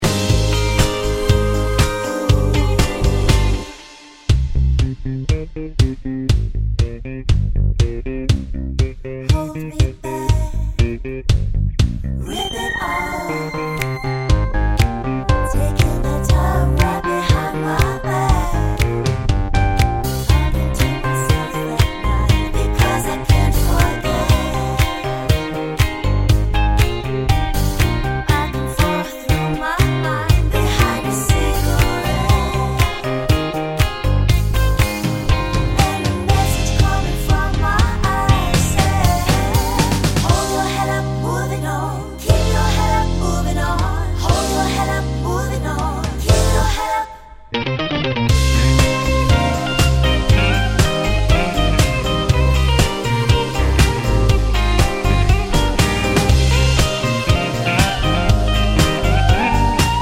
no Backing Vocals Mashups 2:32 Buy £1.50